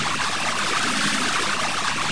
missileflying.mp3